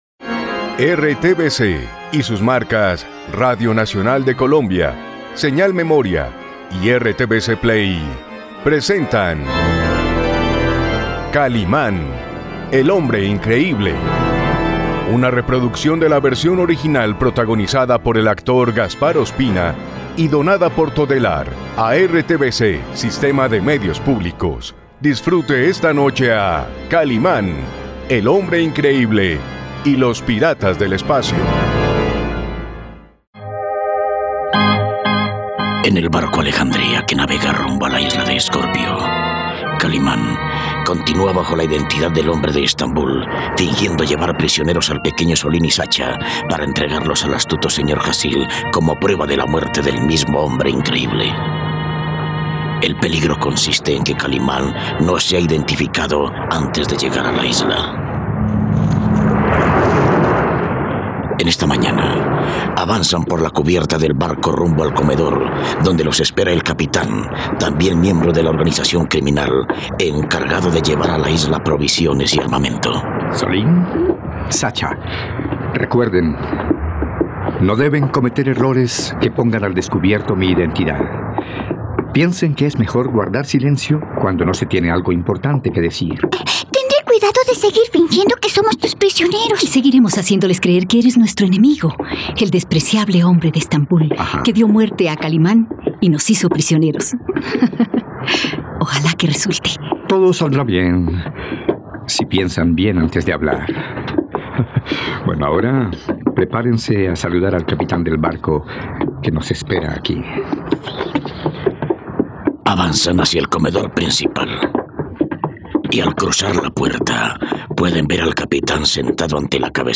..No te pierdas la radionovela completa de Kalimán y los piratas del espacio.